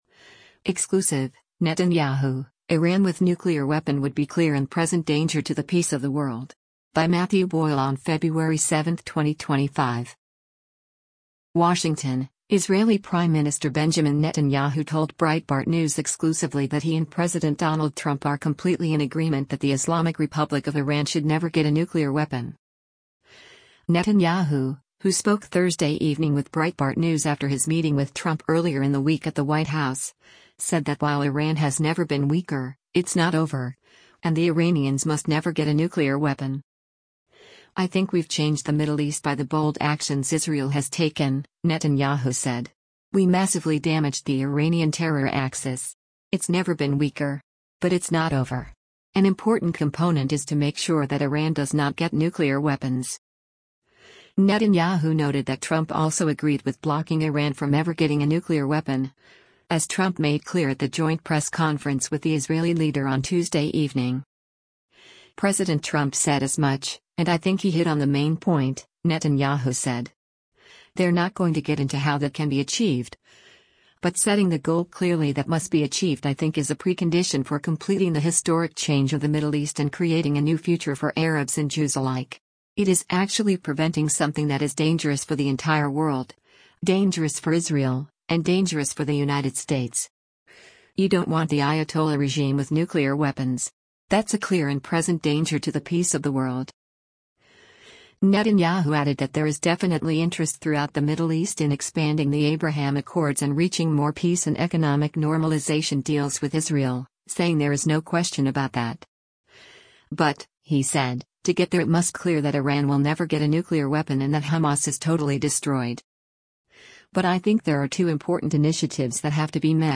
More from Netanyahu’s exclusive interview with Breitbart News is forthcoming.